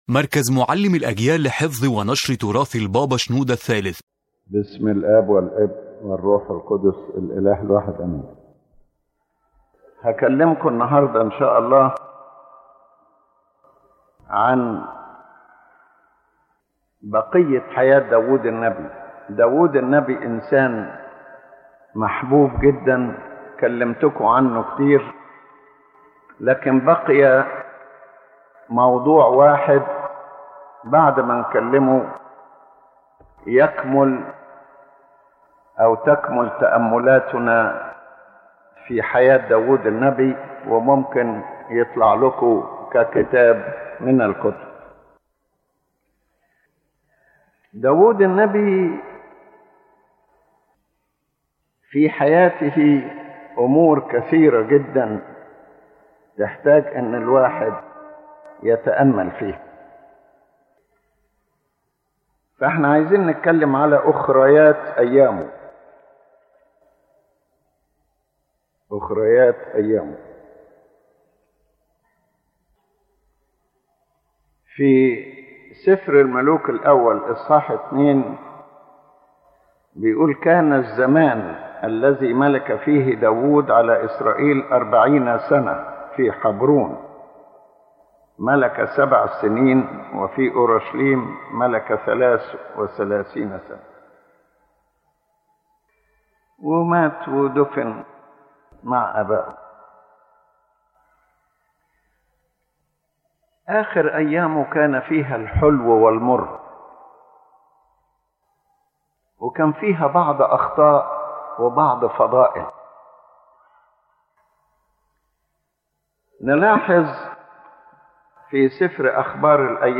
The lecture addresses the last days of the Prophet David and what they carried of victories and defeats, strength and weakness, virtues and mistakes.